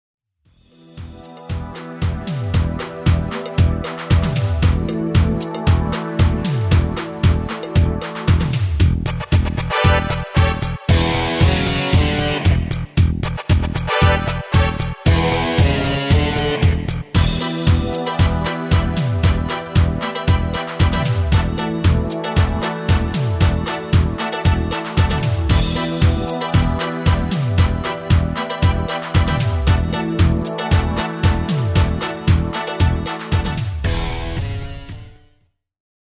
Basic MIDI File Euro 8.50